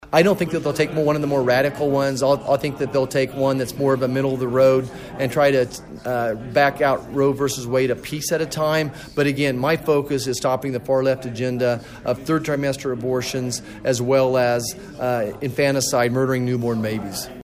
MANHATTAN — Congressman Roger Marshall was back in Manhattan Saturday, hosting a town hall discussion with about two dozen constituents at the Sunset Zoo’s Nature Exploration Place.